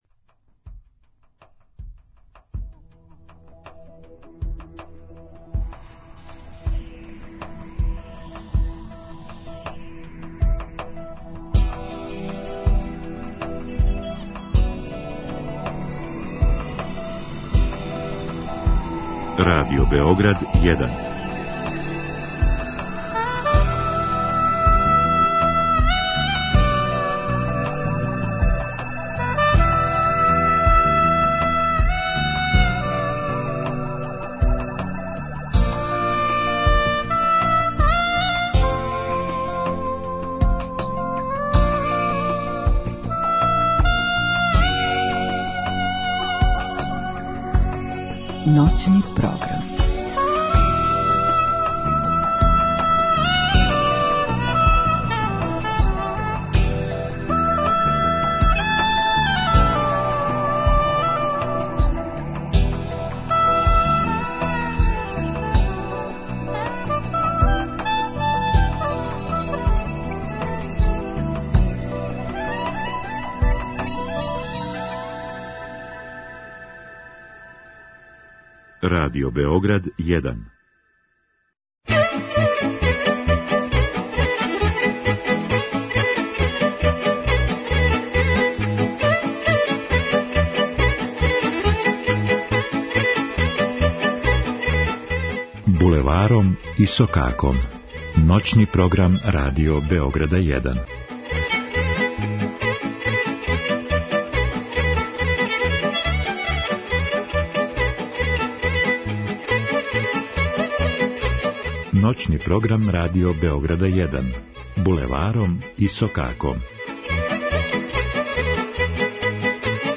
Изворна, староградска и музика у духу традиције.